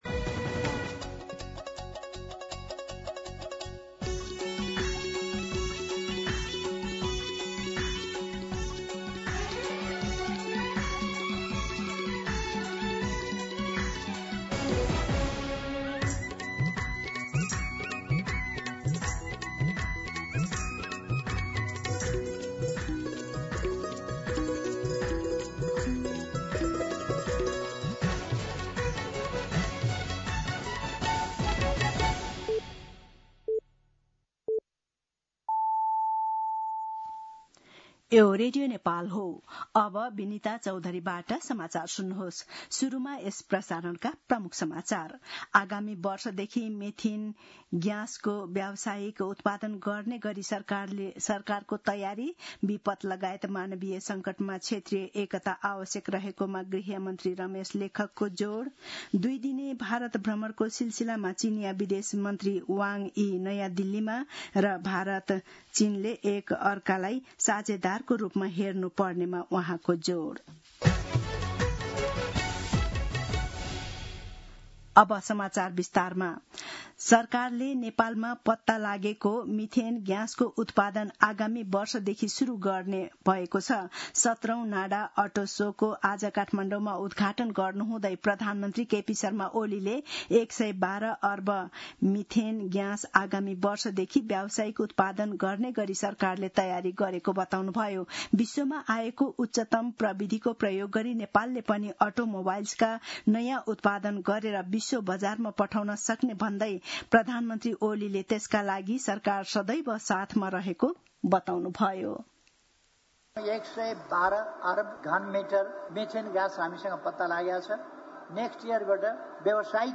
An online outlet of Nepal's national radio broadcaster
दिउँसो ३ बजेको नेपाली समाचार : ३ भदौ , २०८२